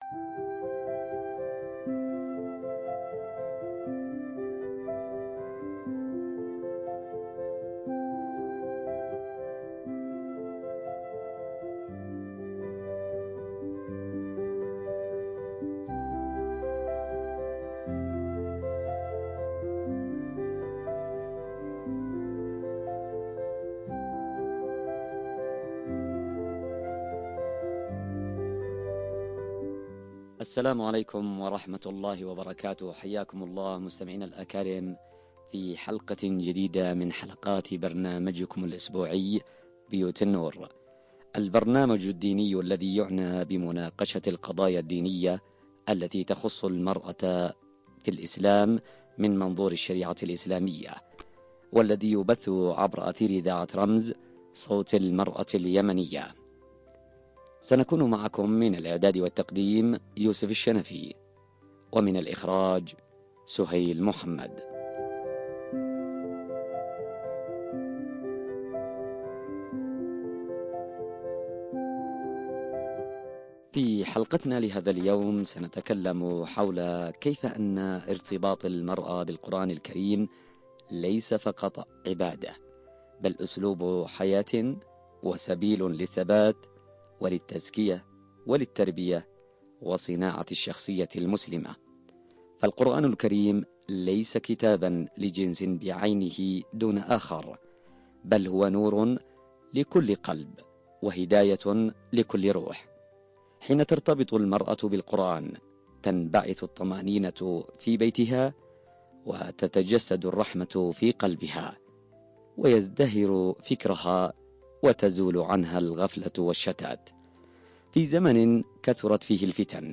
في نقاش إيماني وتربوي
عبر أثير إذاعة رمز